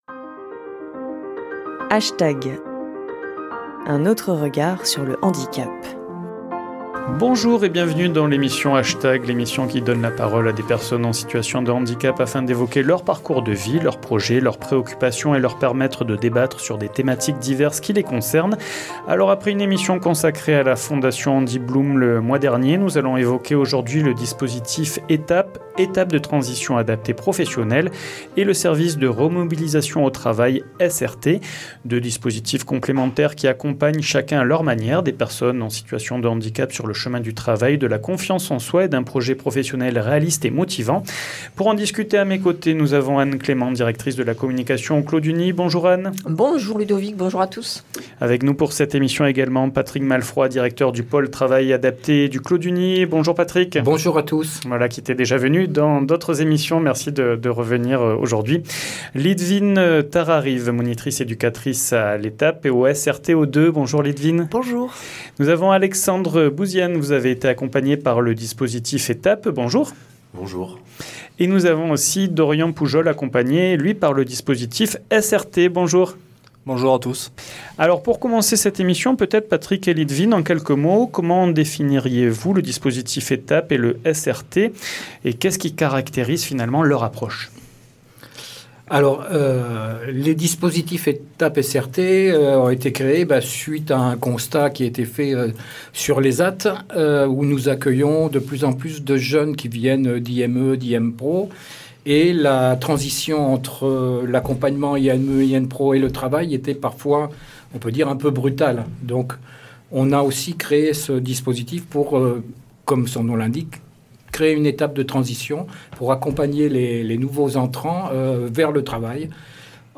Une émission animée